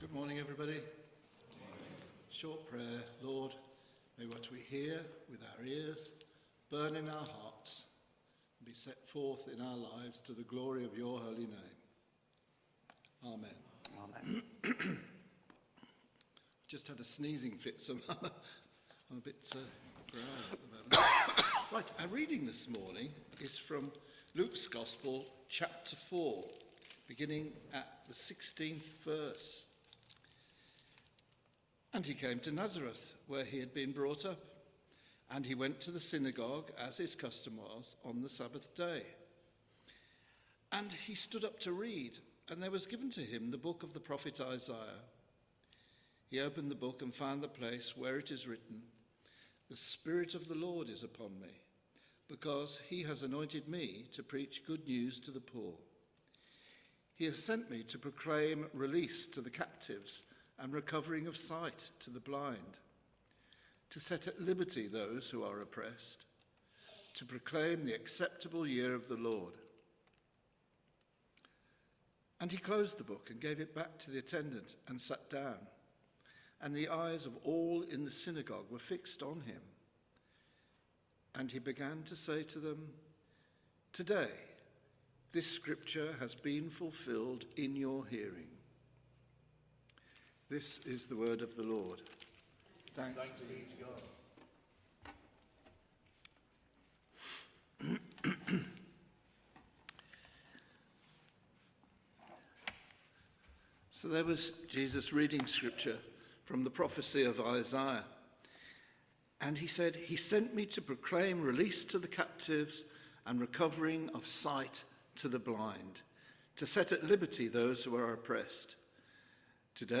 Media for Midweek Communion on Wed 30th Jul 2025 10:00 Speaker